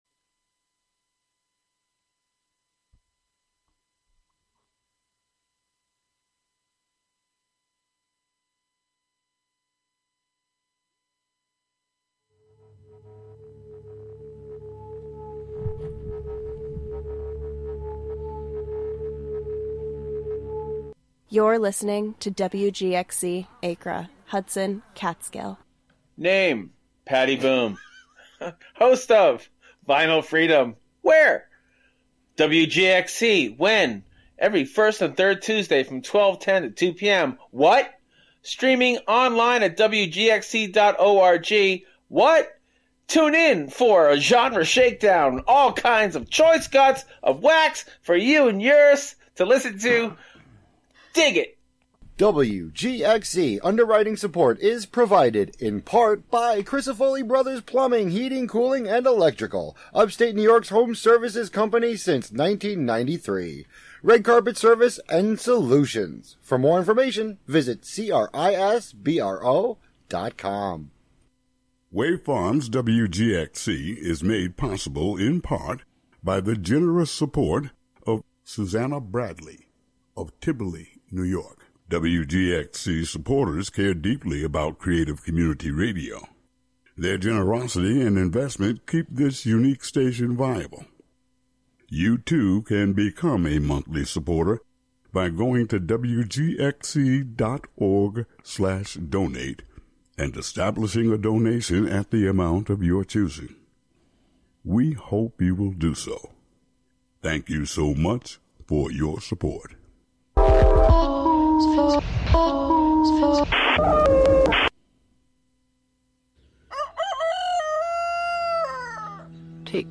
broadcast live from WGXC's Catskill studio.